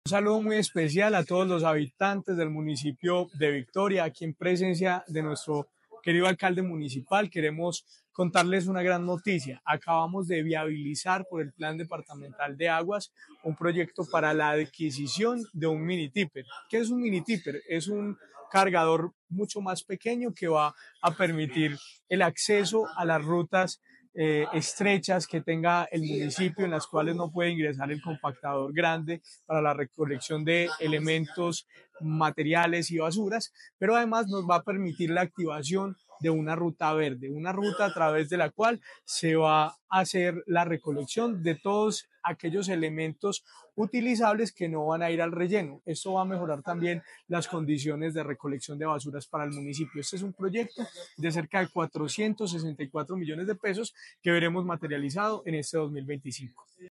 Secretario de Vivienda y Territorio de Caldas, Jorge William Ruiz Ospina.